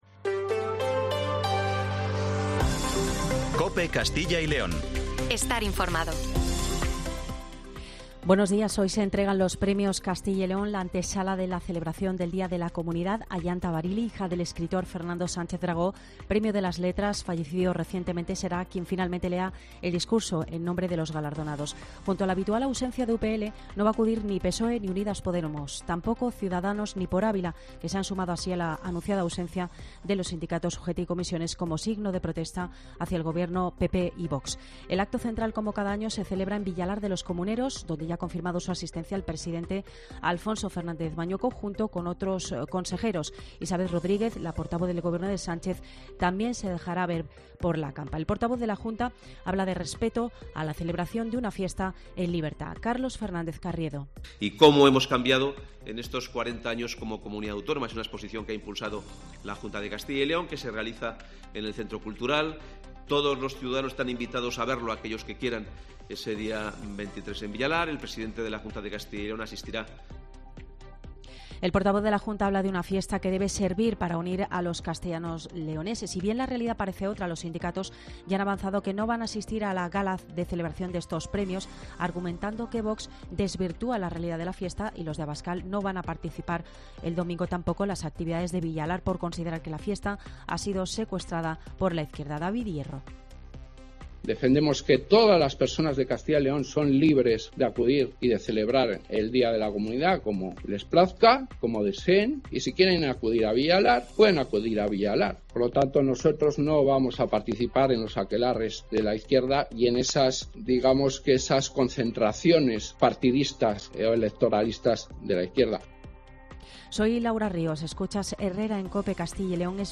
Informativo Matinal 7:50